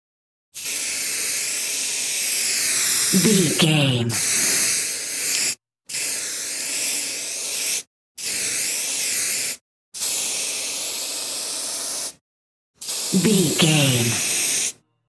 Graffiti aerosol spray large
Sound Effects
foley